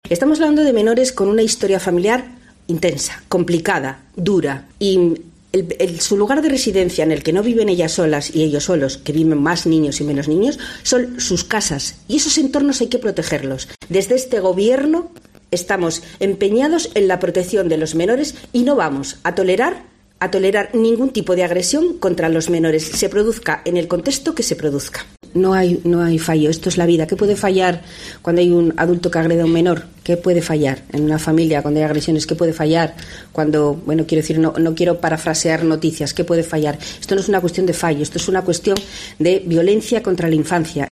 La consejera de Derechos Sociales y Bienestar, Marta del Arco (d), en rueda de prensa.